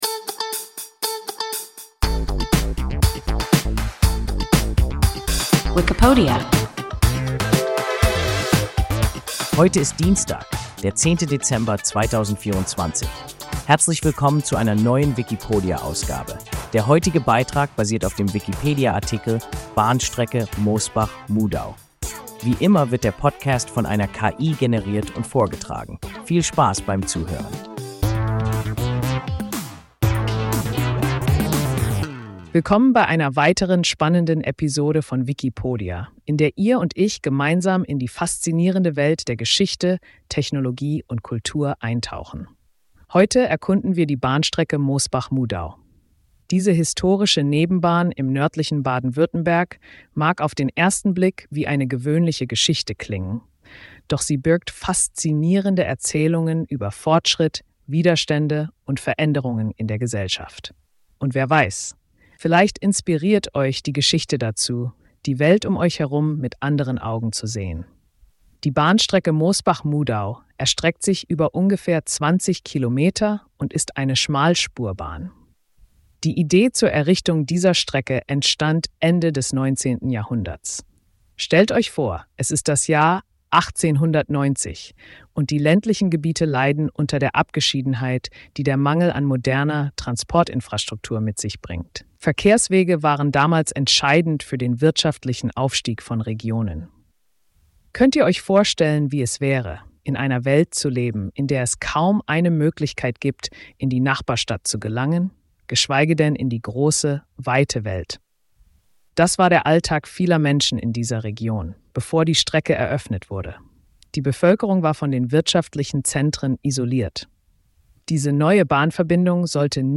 Bahnstrecke Mosbach–Mudau – WIKIPODIA – ein KI Podcast